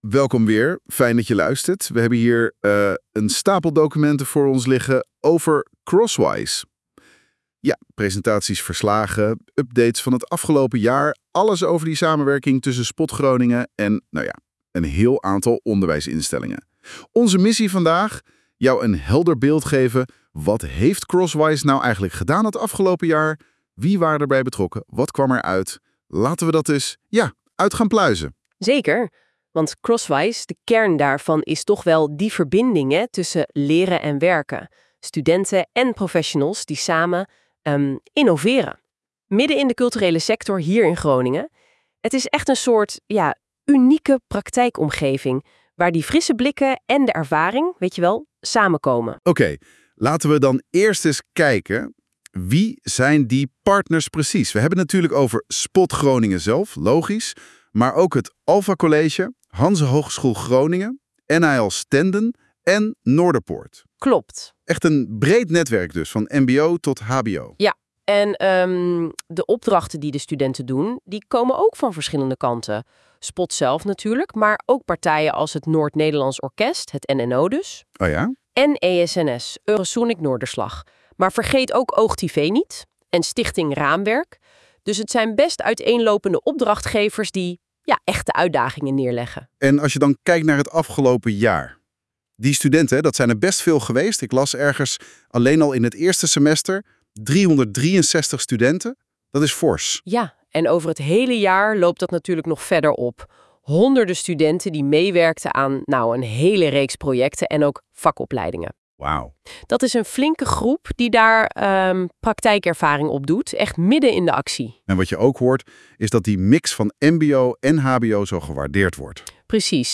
Zowel de podcast als het beeld zijn gegenereerd met behulp van AI